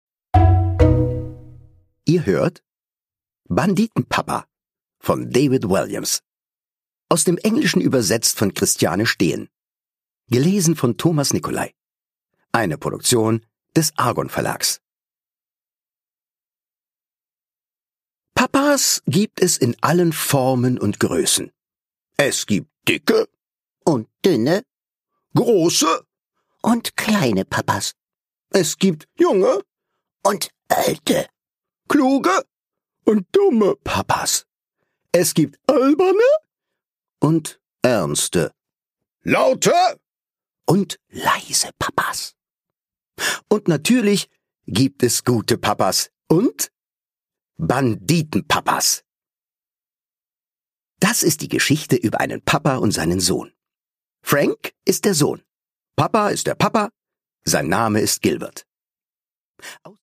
Produkttyp: Hörbuch-Download
Gelesen von: Thomas Nicolai
Thomas Nicolai, Entertainer und Comedian, macht aus der rasanten Kriminal-Vater-Sohn-Komödie von David Walliams ein aberwitziges Hörspiel für die Ohren. Er palavert, brummt und ballert, dass die Fetzen fliegen!